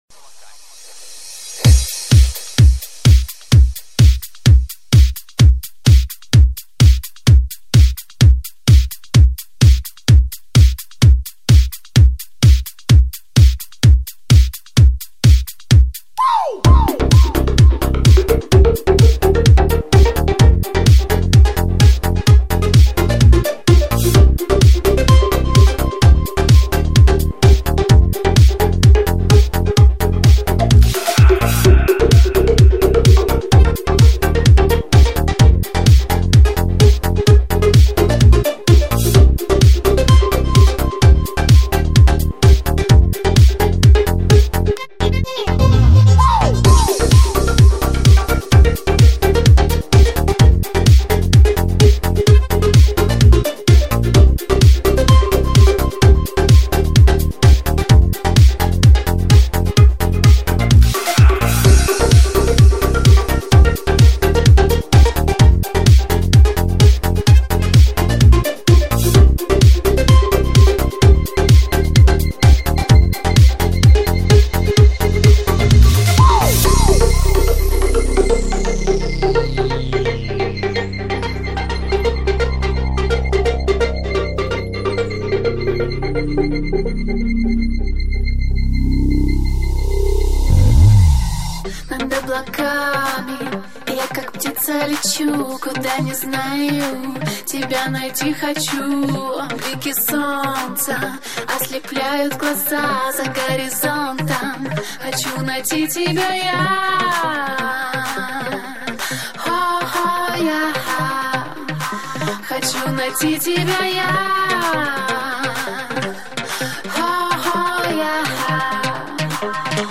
Классный клубный трек!
Стиль: Club House